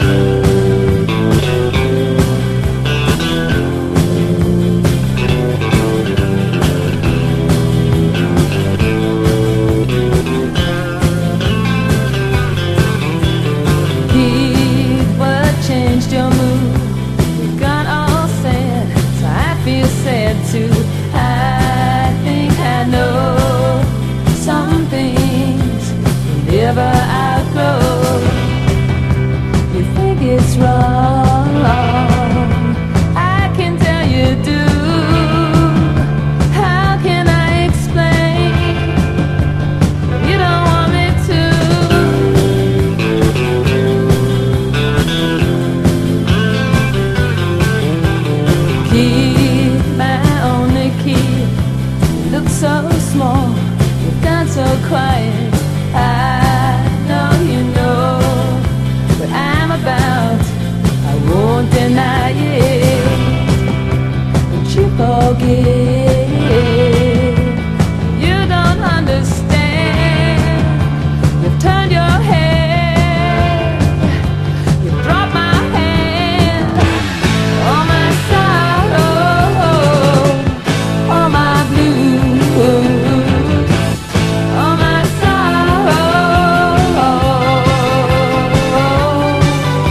¥1,180 (税込) ROCK / 80'S/NEW WAVE.
SYNTH POP
INDIE POP